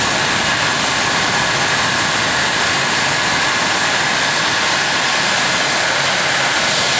turbine_idle_out_R.wav